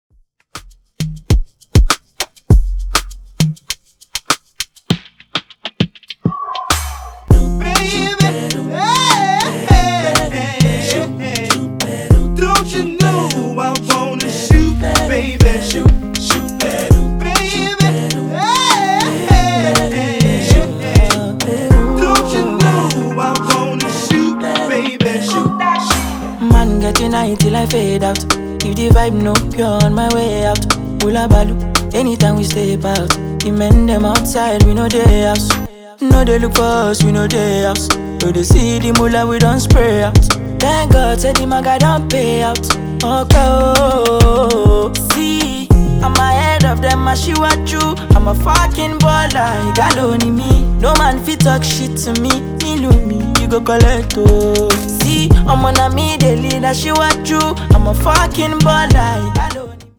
Genres: AFROBEAT , RE-DRUM , TOP40